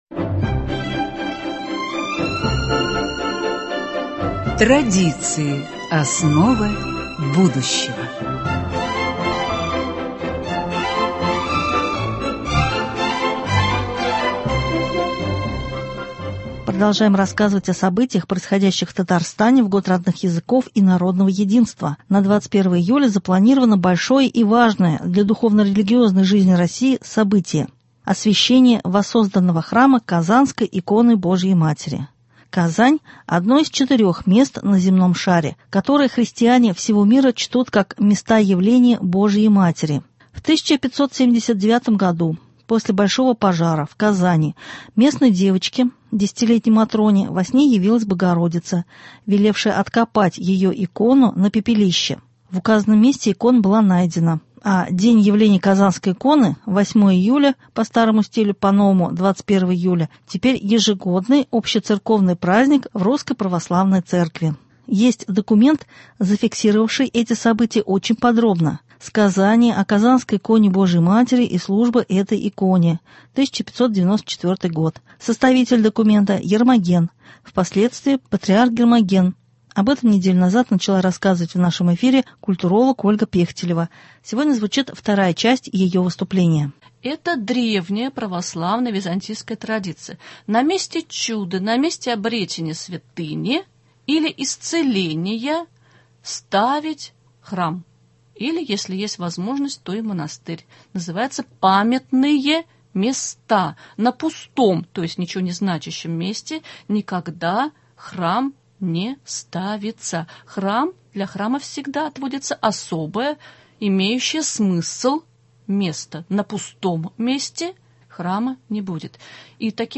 сегодня звучит вторая часть ее выступления